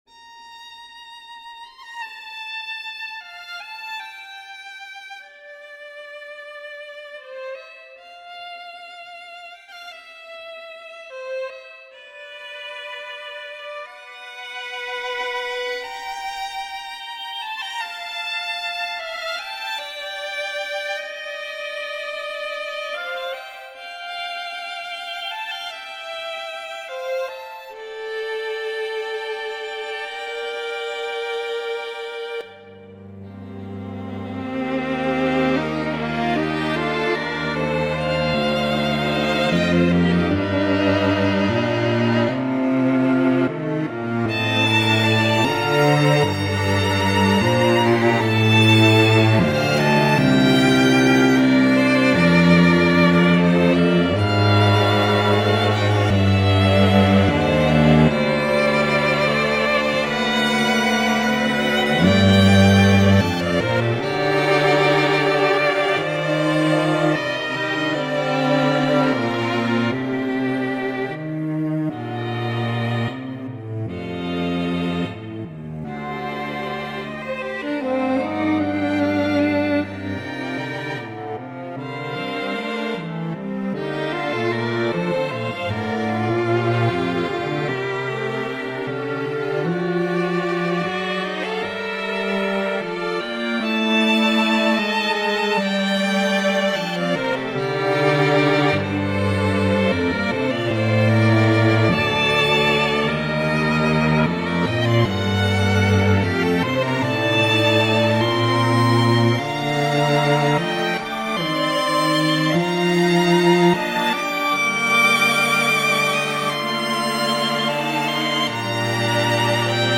Two fragments. The first is a very romantic sort of piece, the second is more minimalist. Both end abruptly, because they're not done.